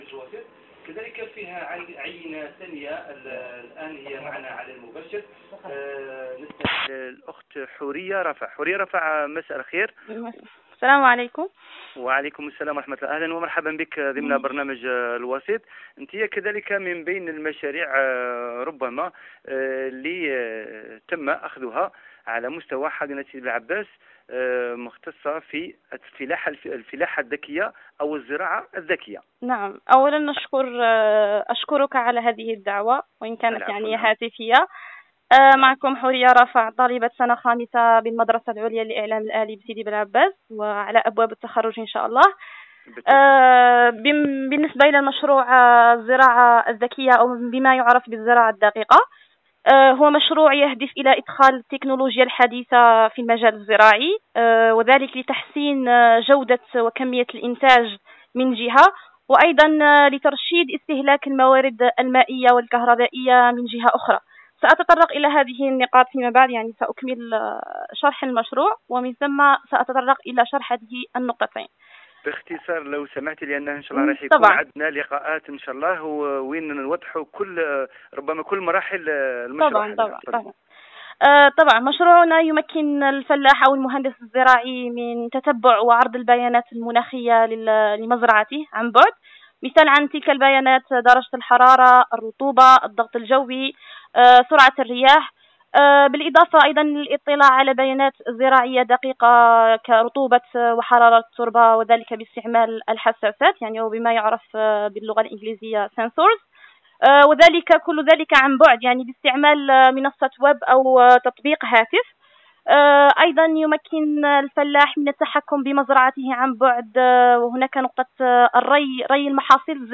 L’enregistrement de l’interview est disponible